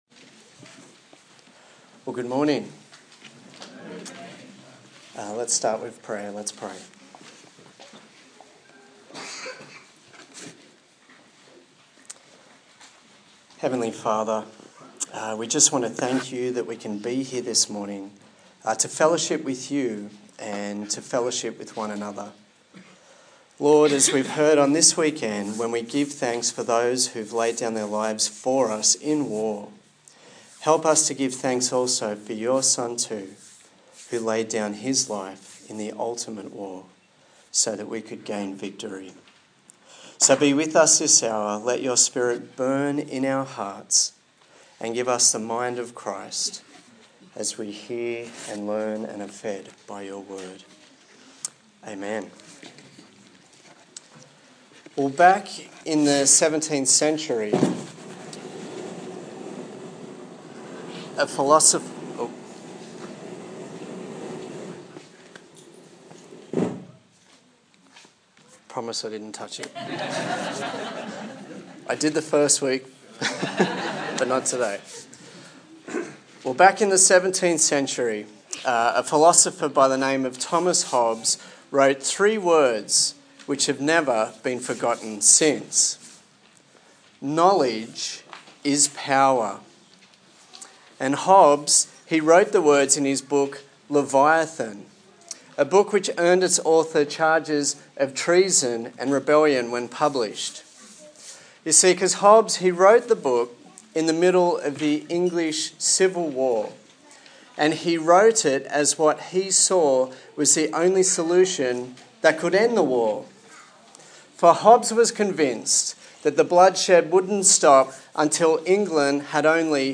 Colossians Passage: Colossians 1:9-14 Service Type: Sunday Morning